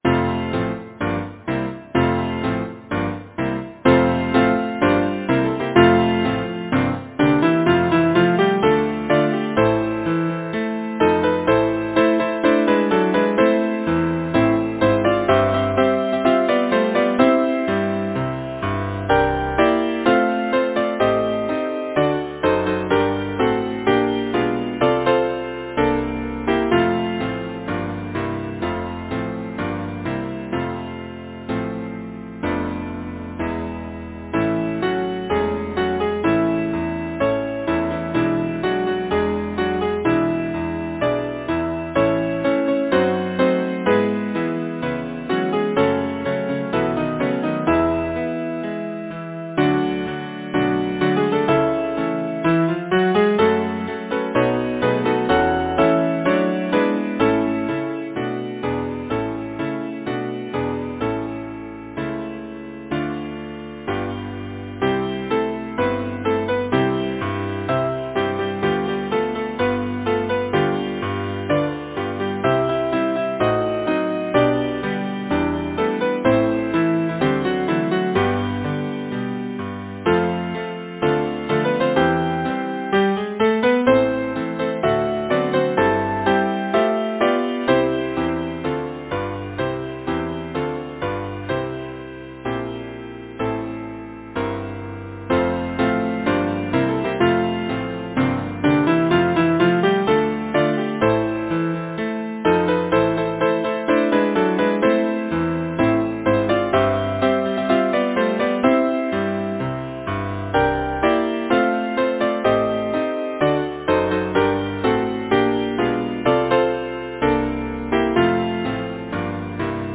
Title: Viking Song Composer: Samuel Coleridge-Taylor Lyricist: David McKee Wright Number of voices: 4vv Voicing: SATB Genre: Secular, Partsong
Language: English Instruments: Piano